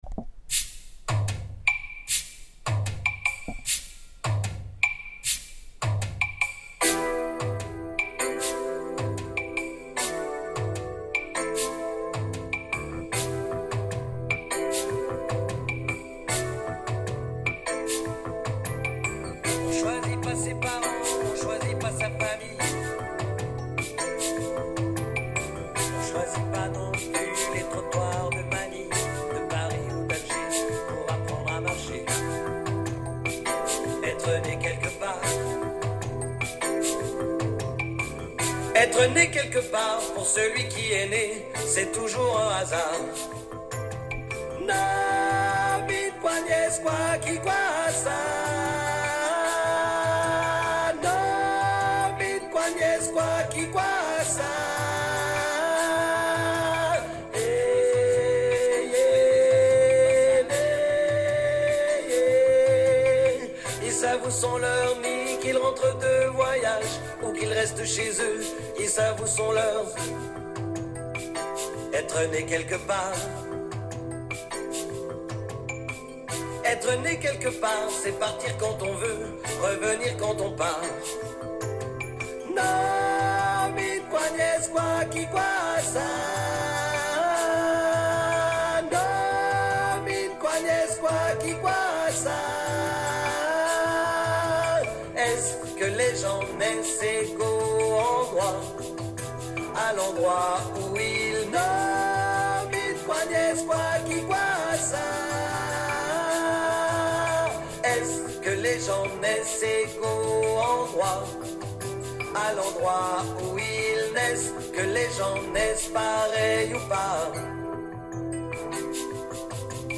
NE QUELQUEPART TENOR
ne-quelquepart-tenor.mp3